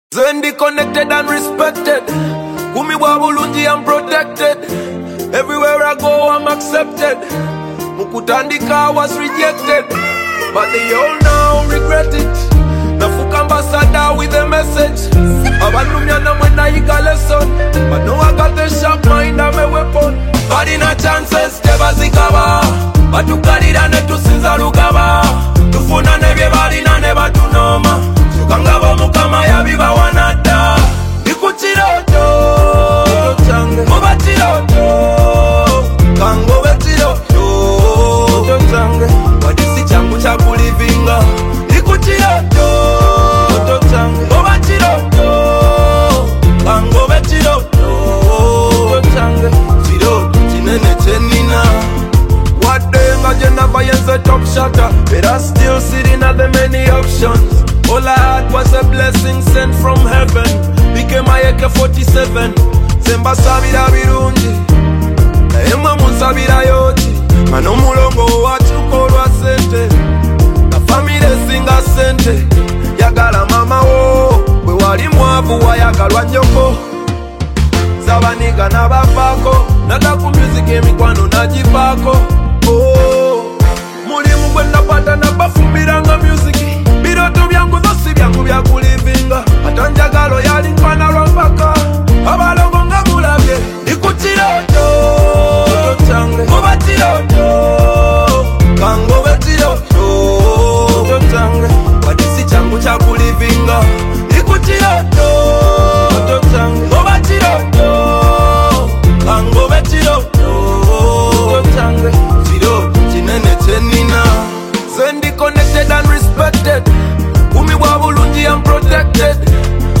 inspirational single